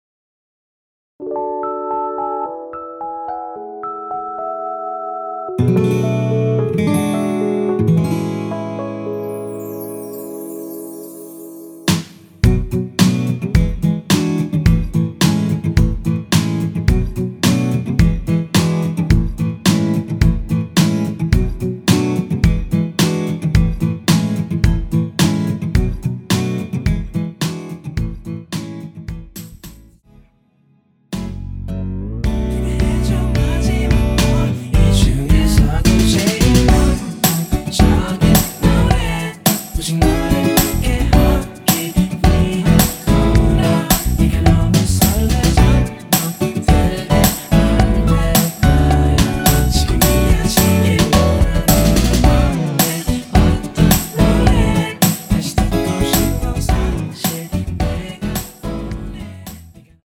원키 코러스 포함된 MR입니다.
앞부분30초, 뒷부분30초씩 편집해서 올려 드리고 있습니다.